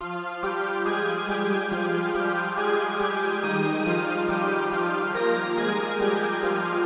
描述：循环听起来很好，有毛茸茸的拍子
Tag: 140 bpm Trap Loops Pad Loops 1.15 MB wav Key : Unknown